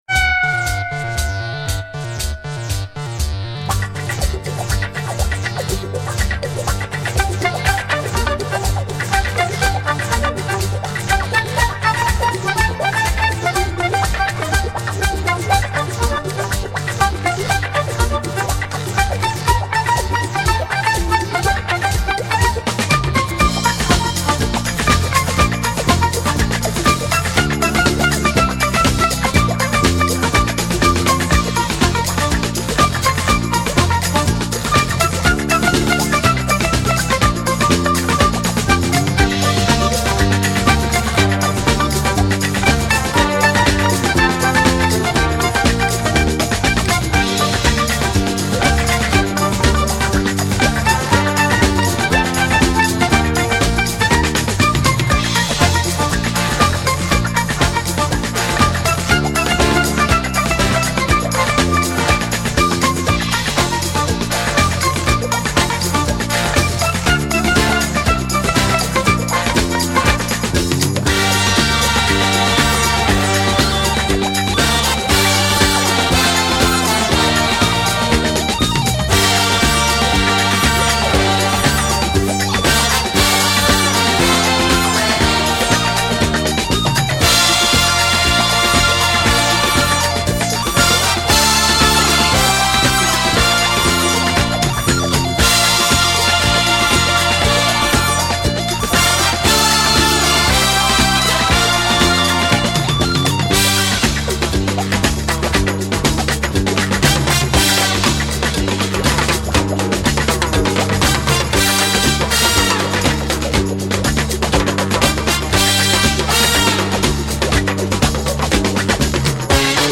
Genre:Instrumental